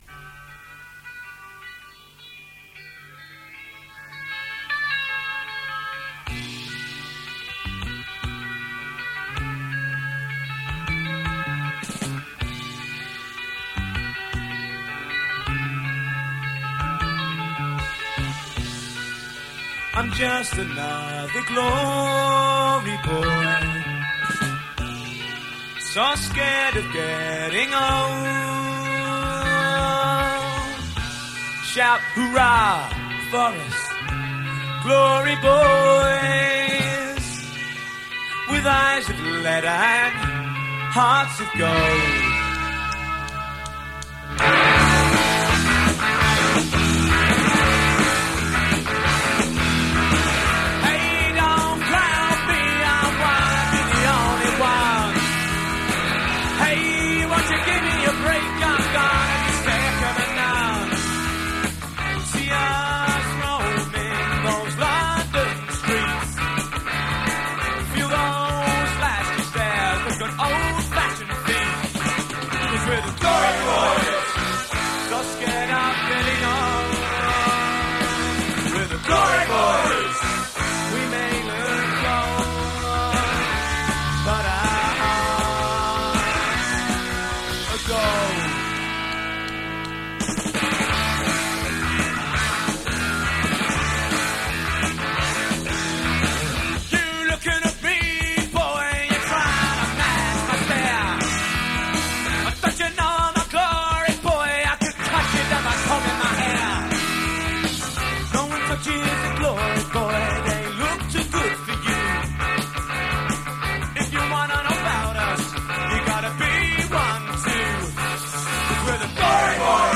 power pop band
Mod Revival